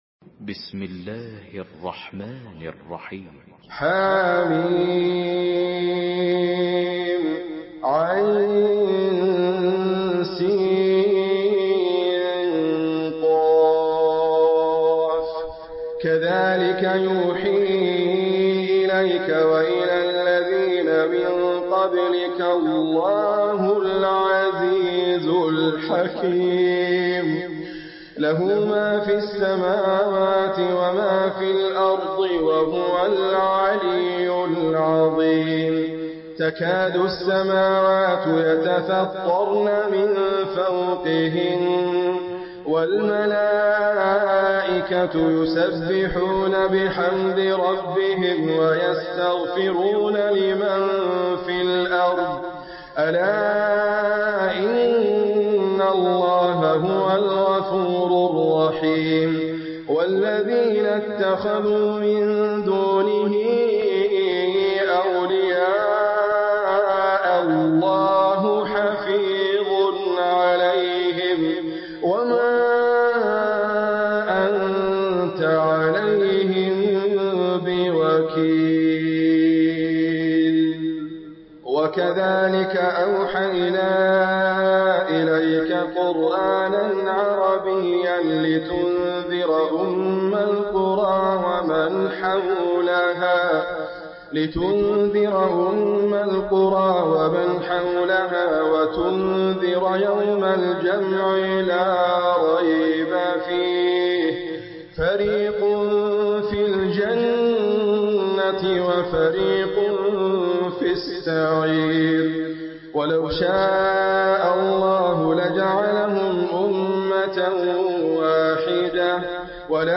تحميل سورة الشورى بصوت إدريس أبكر
مرتل